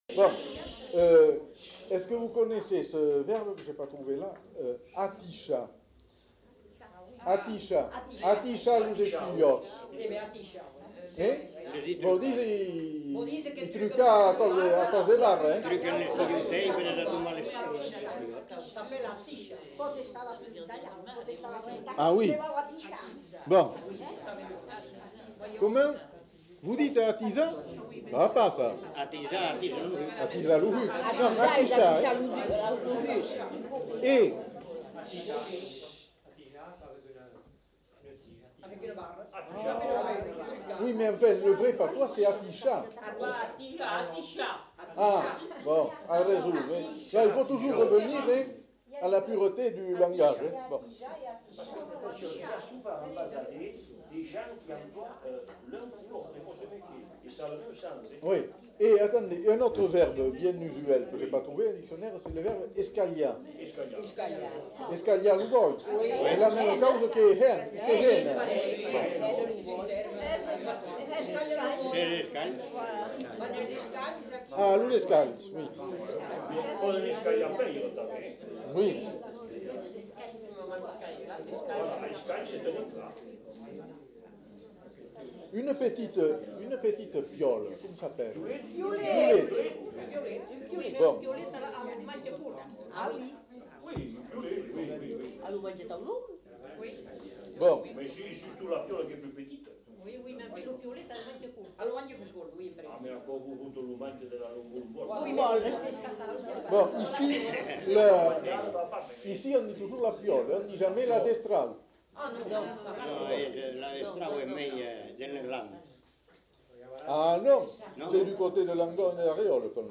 Genre : témoignage thématique
Plusieurs personnes non identifiées.